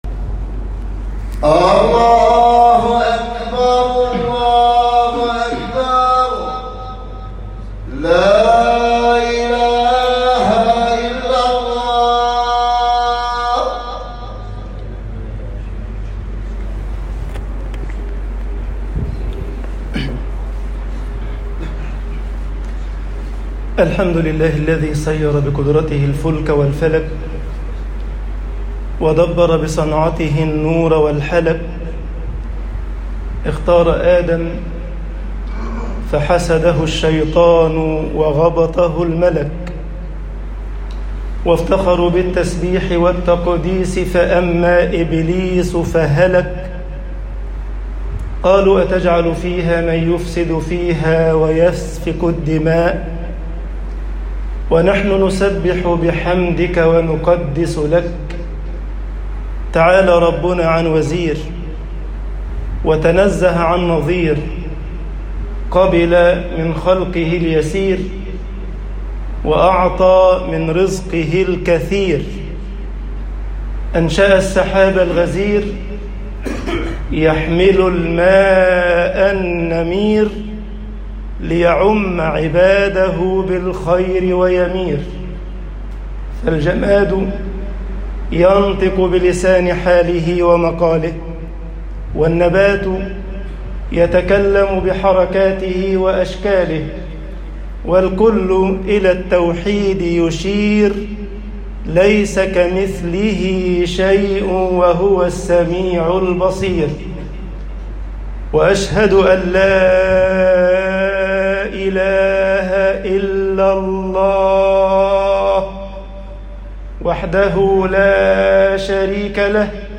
خطب الجمعة - مصر كُنْ وَصُولًا للرَّحِم طباعة البريد الإلكتروني التفاصيل كتب بواسطة